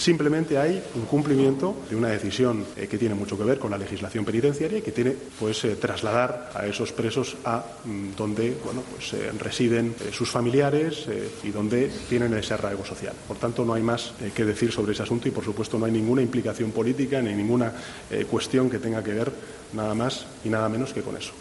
Sánchez ha sido preguntado por la decisión de Interior de autorizar el traslado del exvicepresidente catalán Oriol Juqueras y otros cinco dirigentes independentistas presos a cárceles catalanas en la conferencia de prensa que ha ofrecido en Lisboa junto al primer ministro portugués, Antonio Costa.